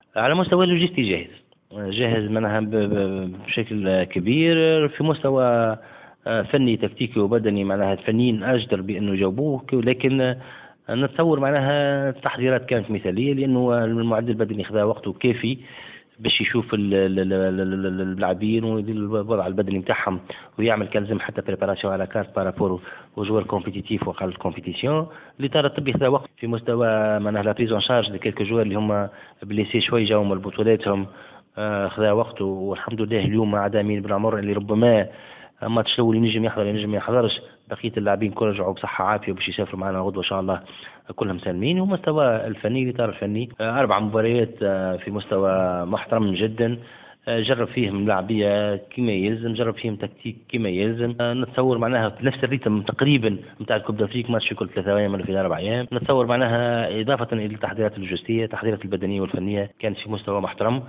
أكد رئيس الجامعة التونسية لكرة القدم وديع الجريء في تصريح لمراسل جوهرة أف أم أن تحضيرات المنتخب الوطني لنهائيات كأس إفريقيا الغابون 2017 كانت مثالية نظرا لمستوى المنافسين في المقابلات الودية وهو ما فسح المجال للإطار الفني لتحديد الخطة المثالية التي سيعتمدها خلال العرس الإفريقي .